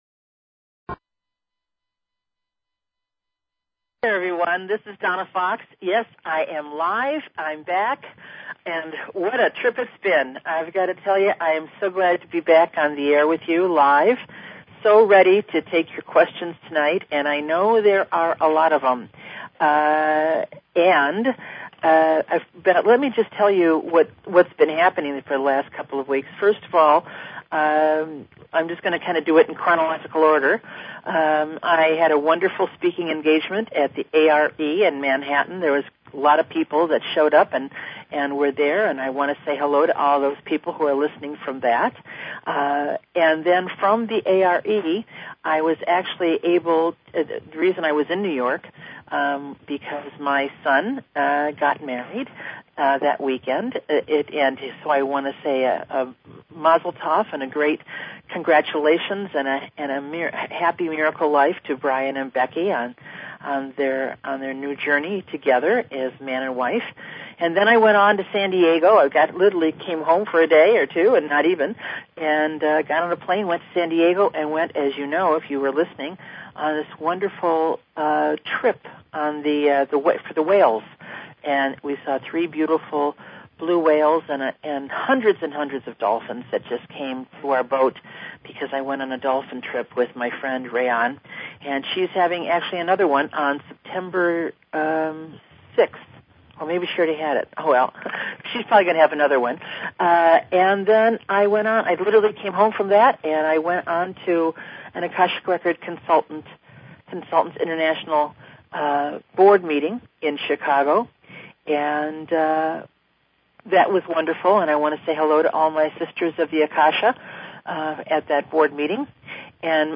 YOU are my guest! This show is dedicated to getting your questions about your life answered and receiving your "Message from the Akasha".